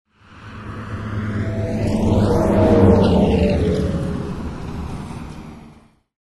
Звук проезжающего на скорости троллейбуса